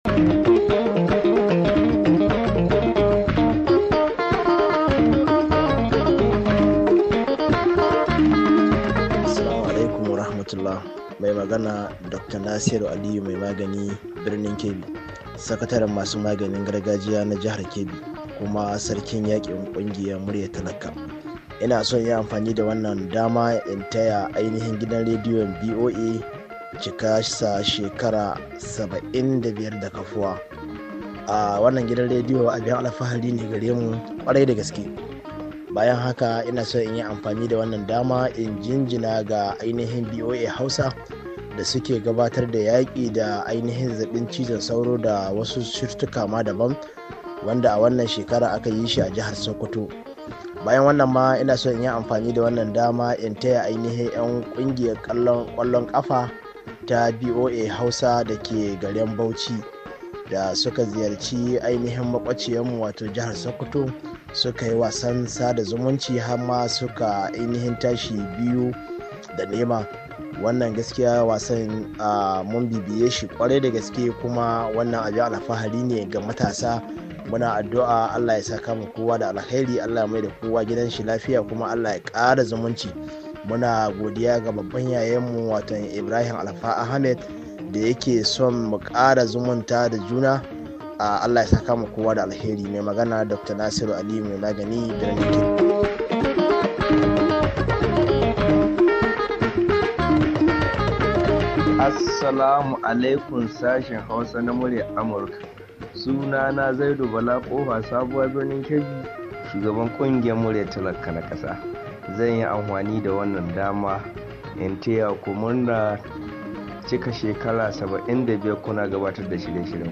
Shirin Sakon Muryoyi Ta WhatsApp Na Masu Sauraren DandalinVOA